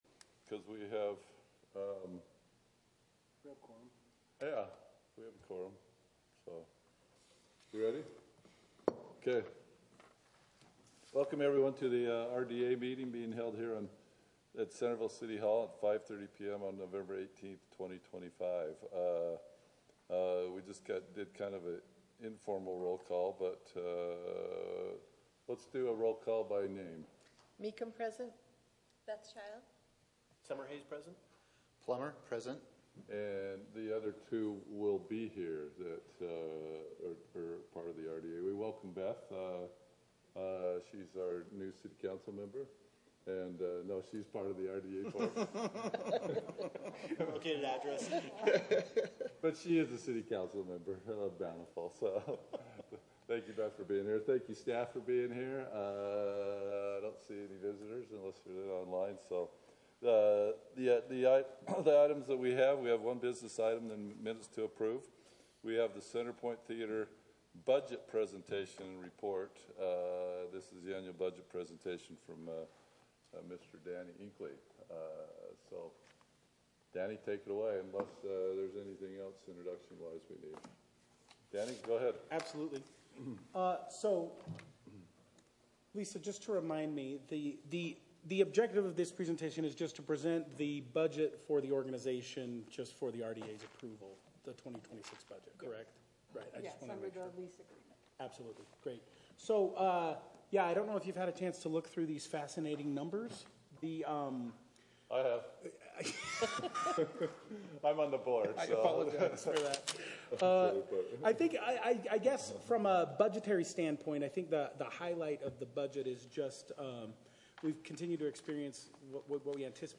NOTICE IS HEREBY GIVEN THAT THE CENTERVILLE REDEVELOPMENT AGENCY WILL HOLD A REGULAR MEETING AT 5:30 PM ON NOVEMBER 18, 2025 AT CENTERVILLE CITY HALL, 250 NORTH MAIN STREET, CENTERVILLE, UTAH.